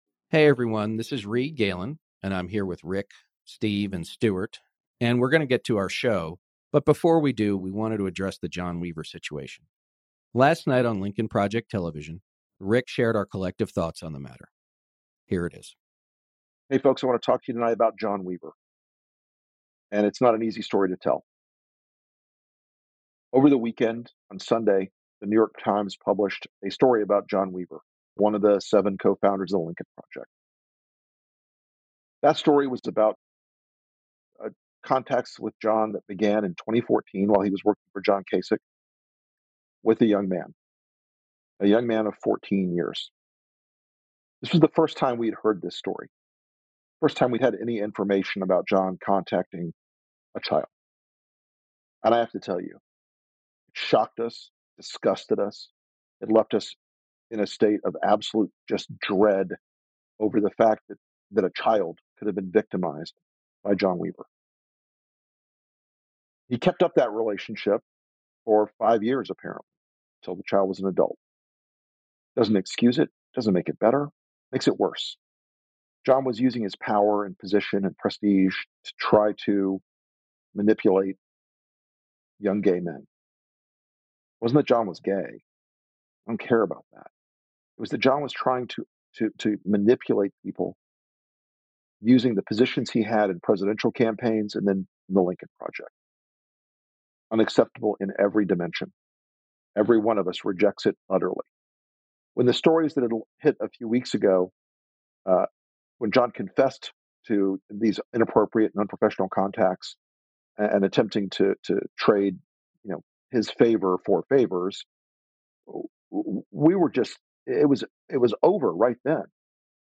ANNOUNCEMENT: At the beginning of this episode, Co-Founder, Rick Wilson shares the Lincoln Project’s collective thoughts on John Weaver.